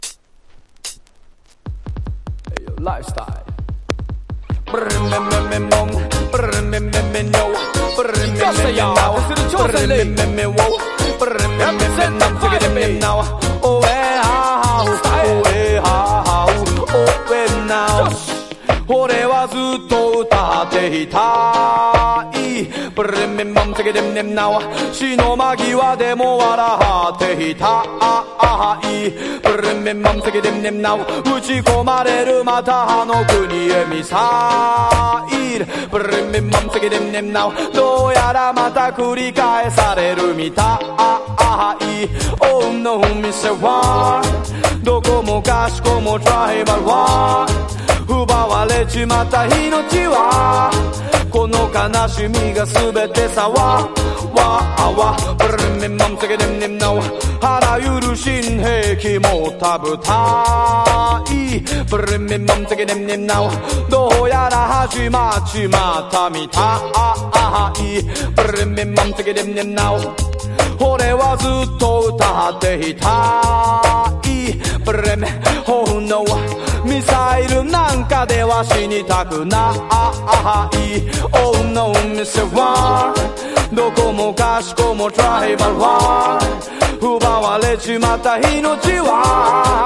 REGGAE / SKA / DUB# DANCE HALL# REGGAE